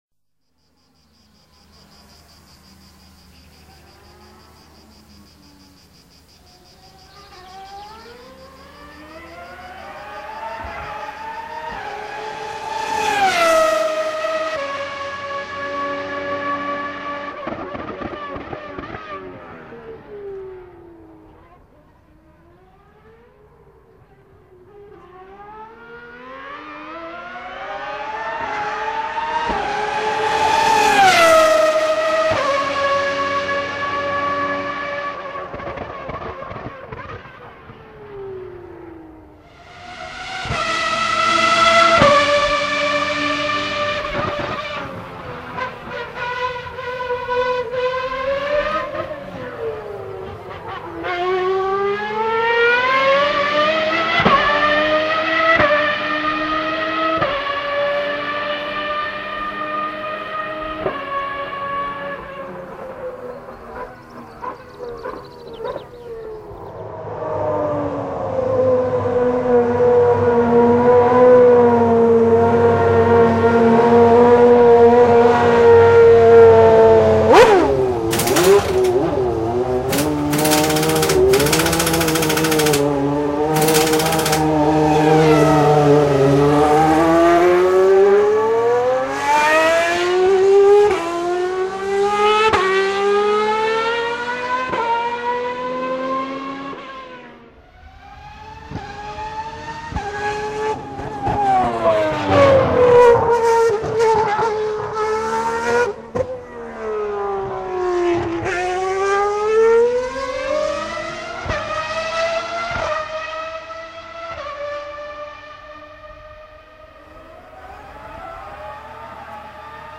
Formule 1 -V12 geluid
Formule1-V12.wma